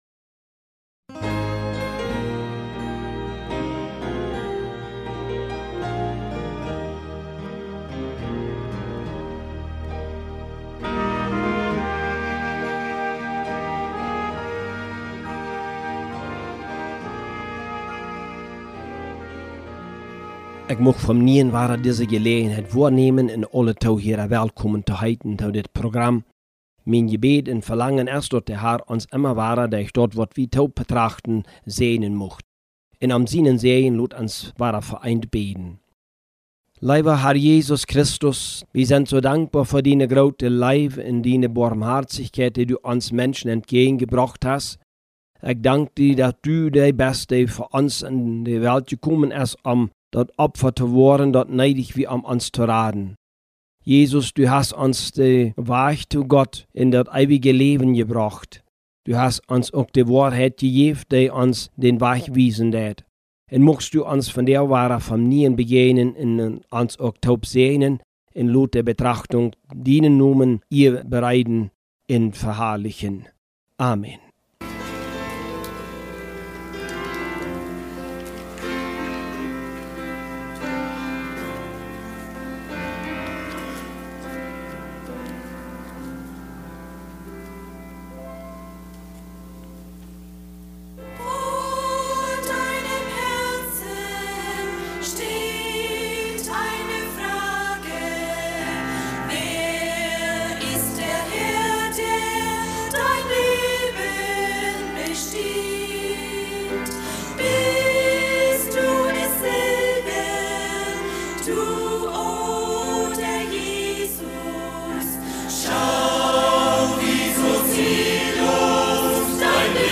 PLATDEUTSCHE Predigten Matthäus https